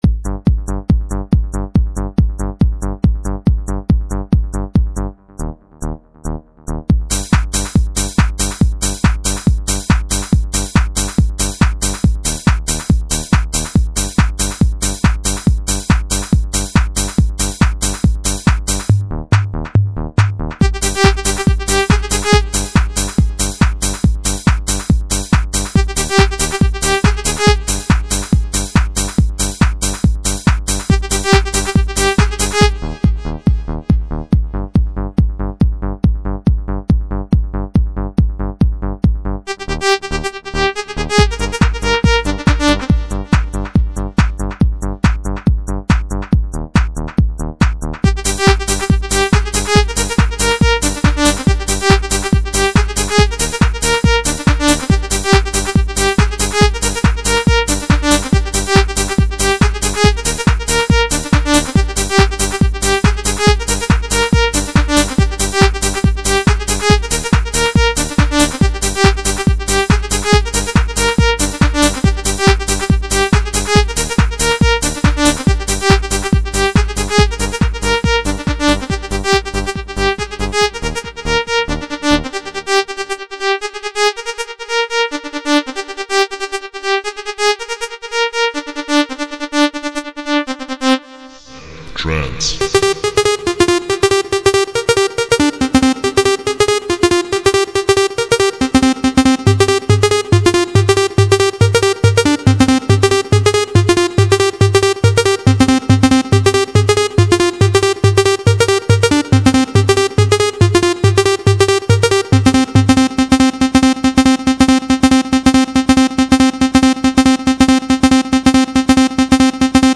dance/electronic
Dancehall